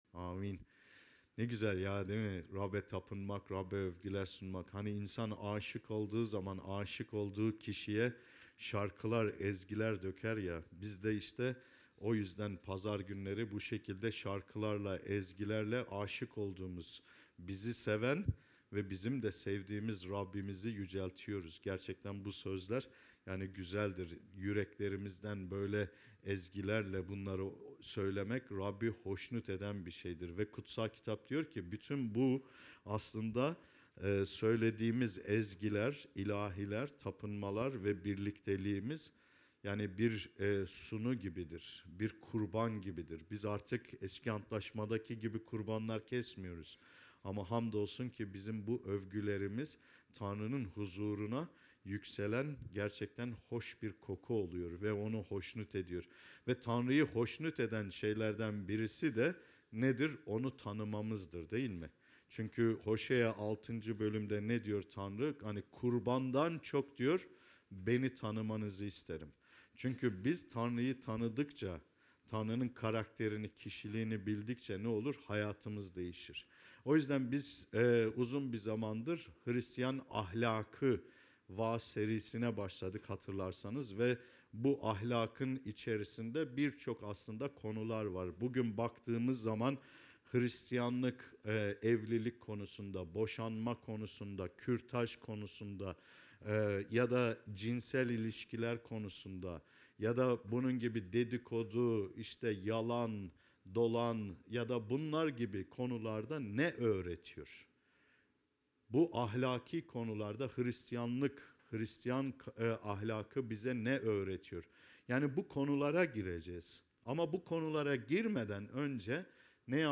HRİSTİYAN AHLAKI VAAZ SERİSİ NO:4